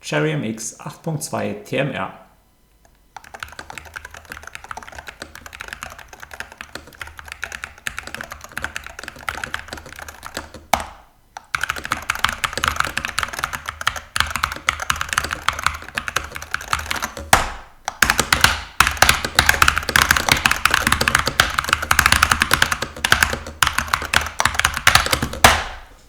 Die MX 8.2 funktioniert an sich, „angenehm leise“ ist sie wie vom Hersteller versprochen allerdings nicht.
Akustik: Einfacher Aufbau, einfacher Klang
Die MX 8.2 bewegt sich auf der hellen, leicht klapprigen Seite des Spektrums und fängt Schall nicht ganz so restlos ein, wie das bei einer 200-Euro-Tastatur wünschenswert wäre. Heller Kunststoff-Hall mutet eher günstig an.
Heller, nicht präzise klackender, nicht unauffälliger Klang bewegt die Tastatur im besten Falle in die Belanglosigkeit.